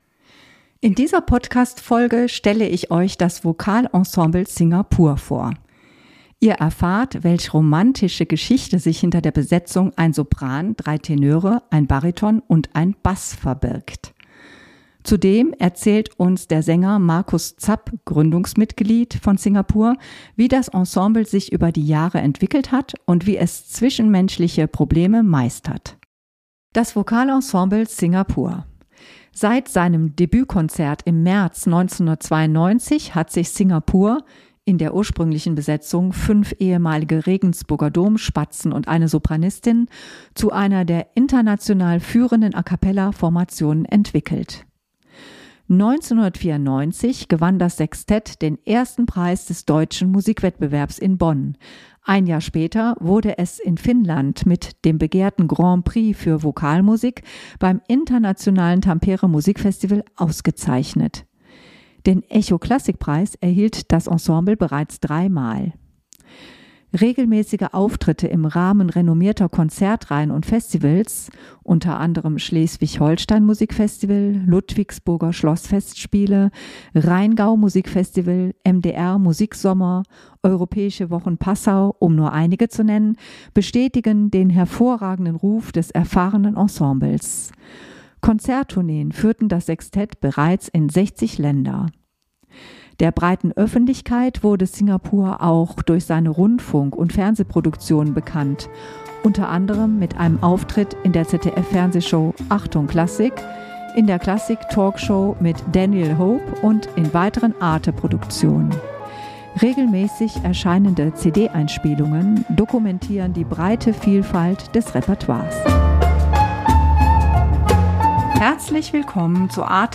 004 Kreativ. Emotional. Vielseitig.